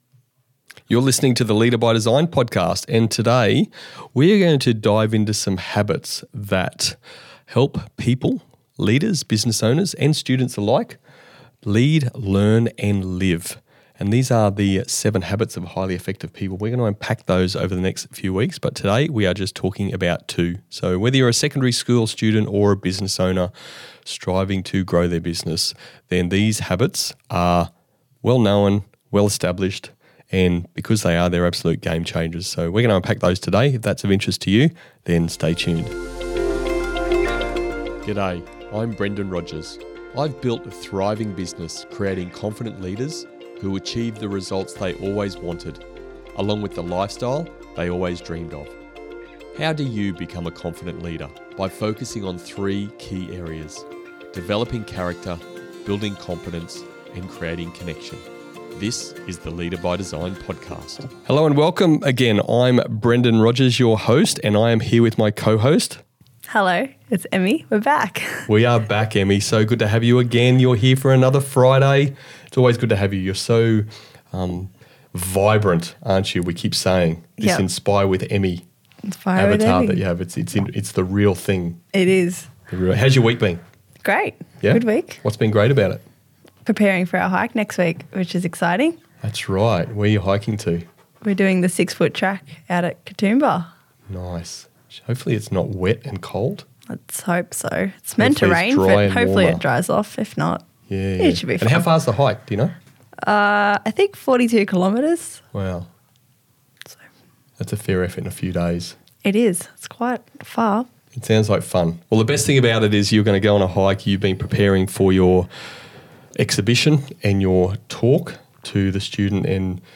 Join the Leader by Design podcast as we unpack two transformative habits from Stephen Covey's Seven Habits of Highly Effective People: being proactive and beginning with the end in mind. With co-host
conversation